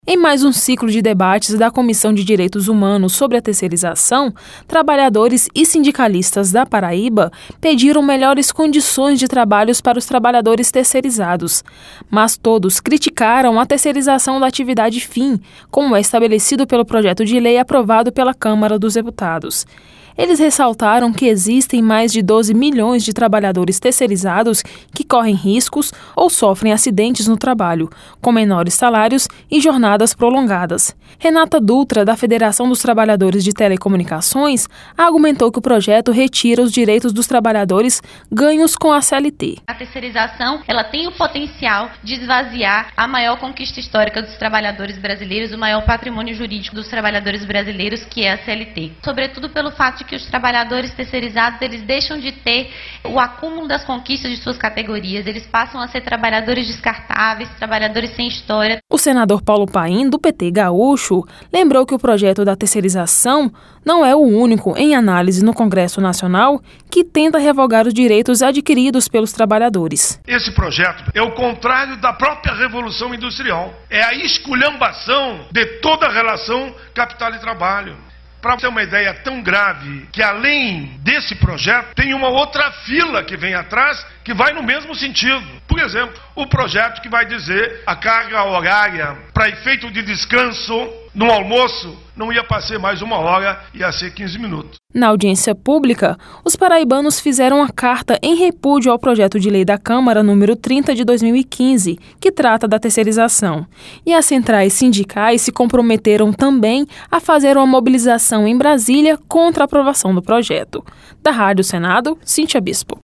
Audiência CDH